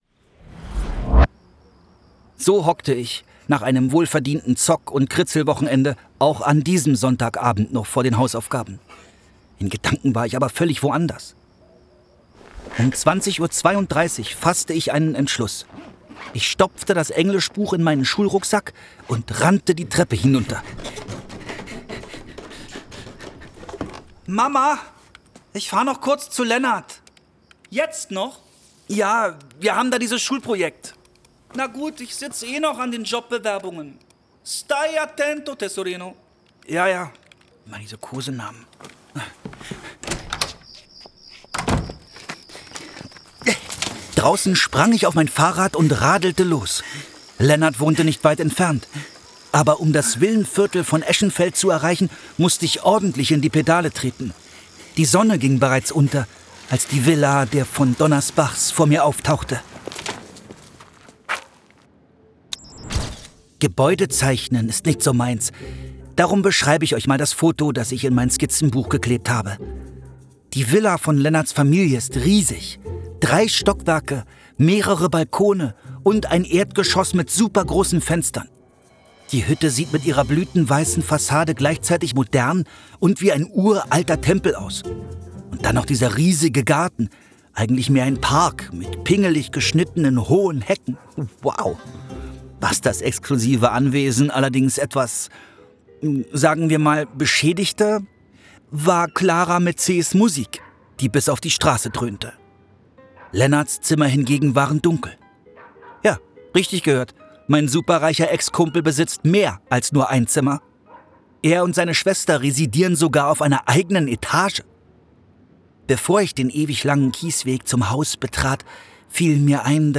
Hörbuch: KoboldKroniken 1.
KoboldKroniken 1. Sie sind unter uns! Daniel Bleckmann (Autor) Stefan Kaminski (Sprecher) Audio-CD 2023 | 2.